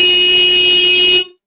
Car Horn
A standard car horn blast with sharp attack and sustained tone
car-horn.mp3